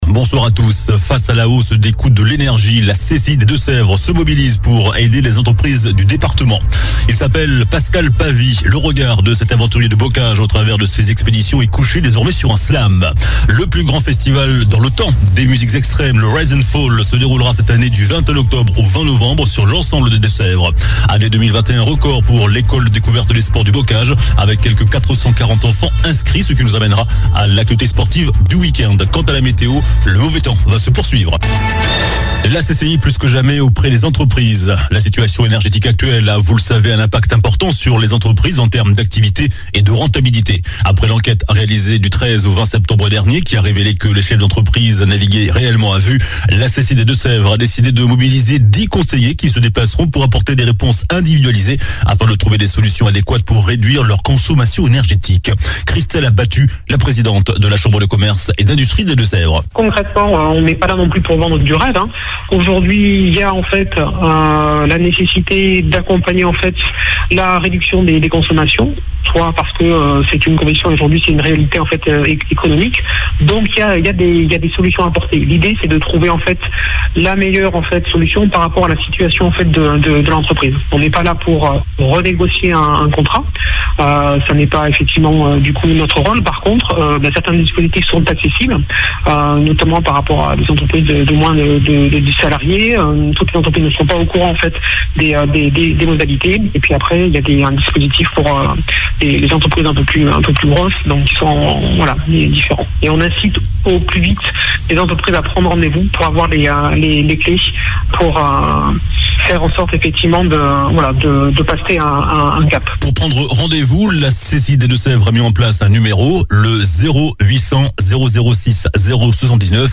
Journal du vendredi 14 octobre (soir)